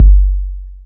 Waka KICK Edited (51).wav